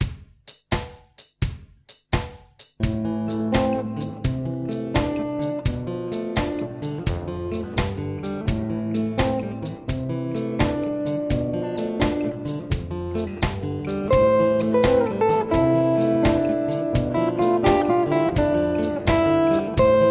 Piste guitare (midi) tablature midi